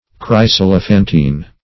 chryselephantine - definition of chryselephantine - synonyms, pronunciation, spelling from Free Dictionary
Chryselephantine \Chrys`el*e*phan"tine\, a. [Gr. chryso`s gold +